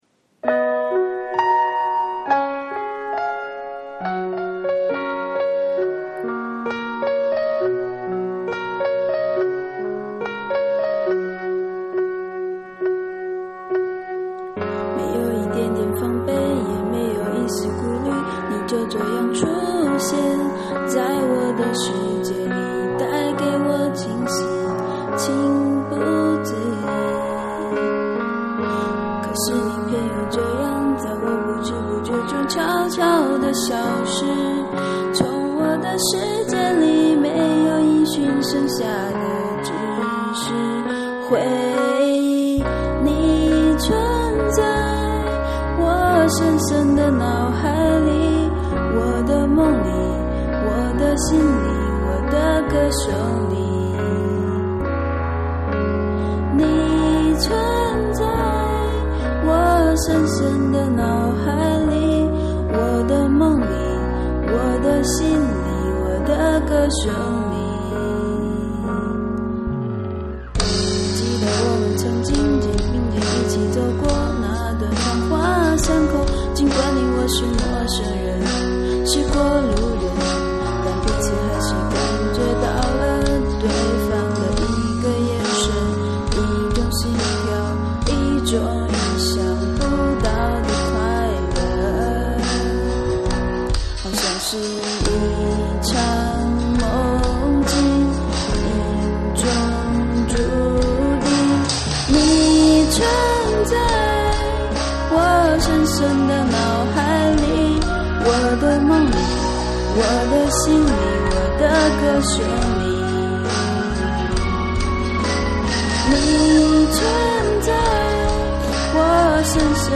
各种跑掉加破嗓，你们无视我给其他人投票去吧。